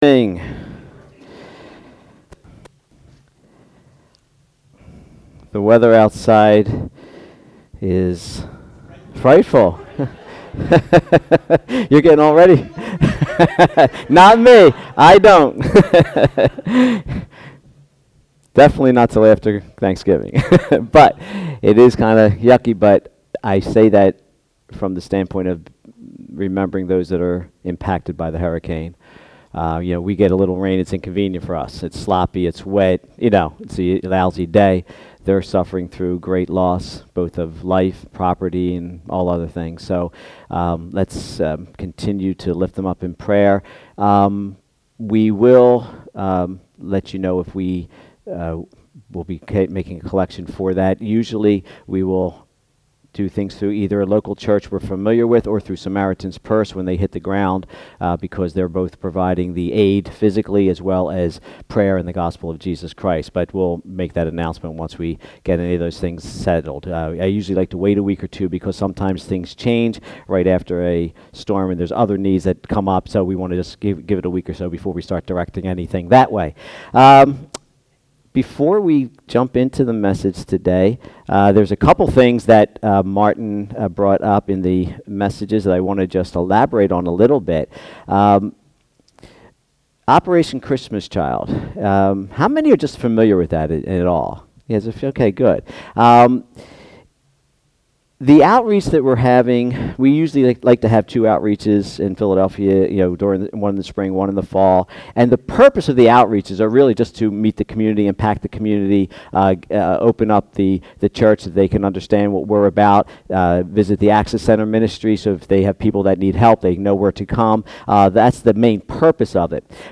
Sermons | Bethel Christian Church